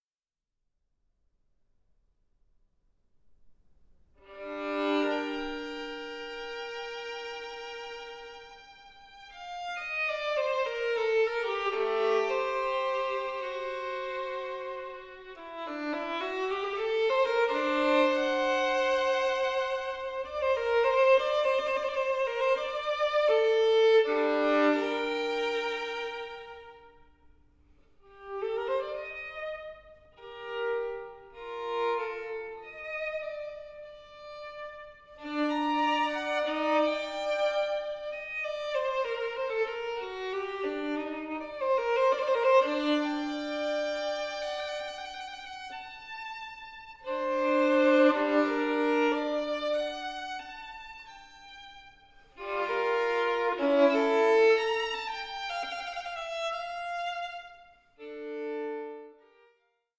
Violin Sonata No. 1 in G Minor